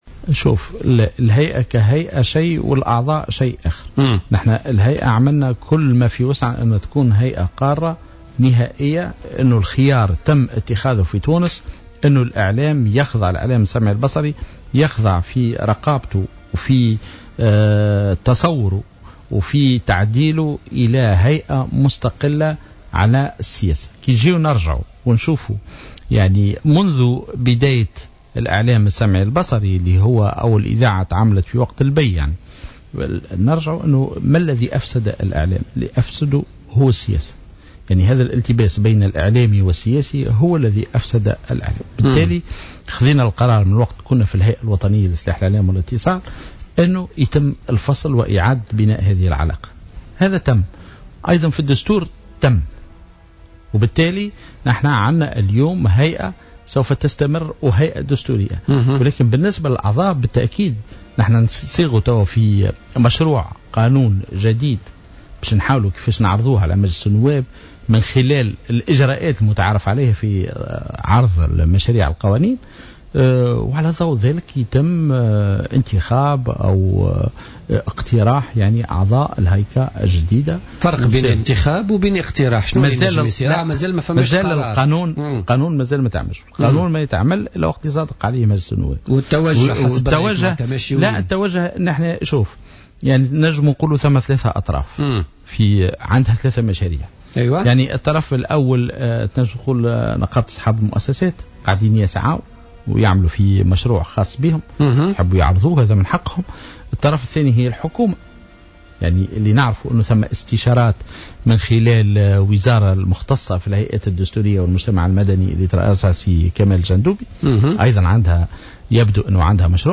واضاف السنوسي، ضيف بوليتيكا اليوم الخميس، إنه لم يتم بعد تقرير آليات تنصيب مجلس جديد للهيئة، سواء بالتعيين أو بالانتخاب في انتظار أن يعرض الأمر على أنظار مجلس نواب الشعب، مضيفا أن استشارة ستعقد في الخصوص بمشاركة كافة الأطراف المعنية بالقطاع الاعلامي.